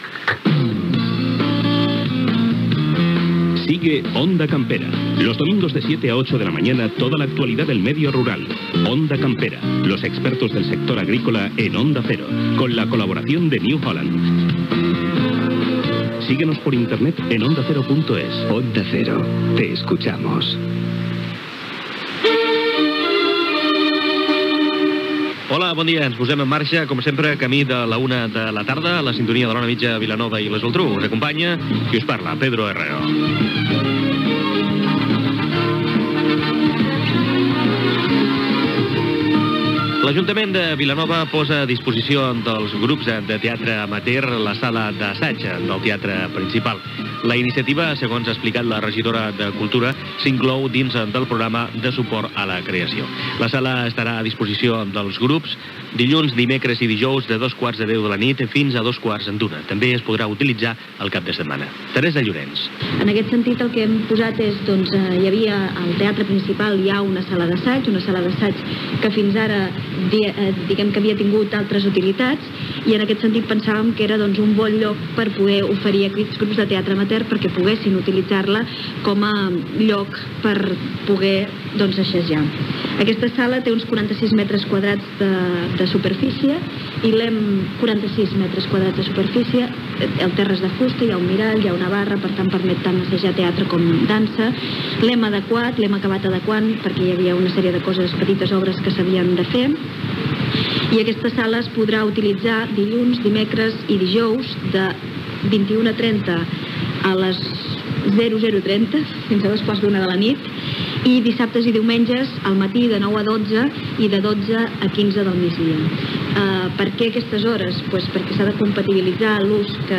Esports: final de la copa del Rei d'hoquei Gènere radiofònic Informatiu